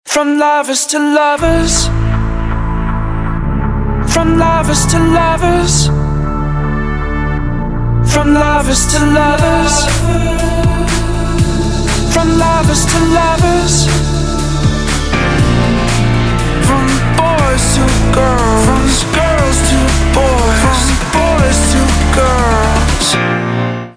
• Качество: 256, Stereo
dance
club
house